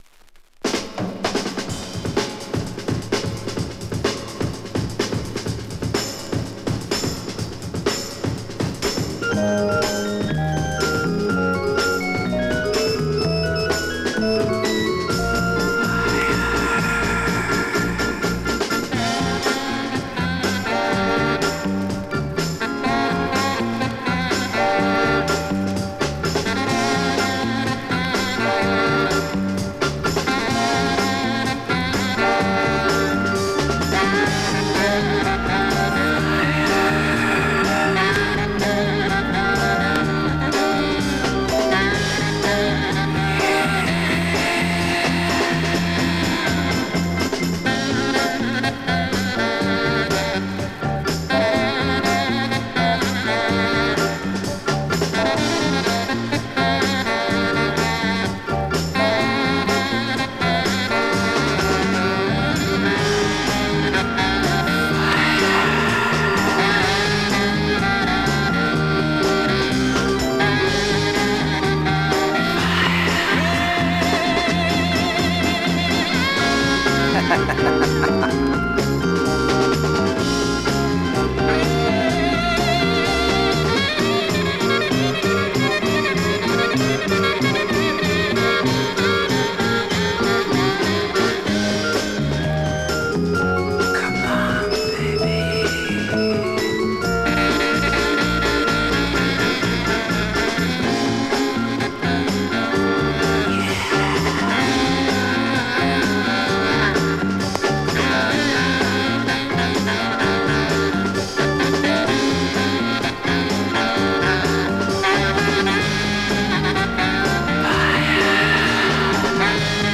> JAZZ FUNK/RARE GROOVE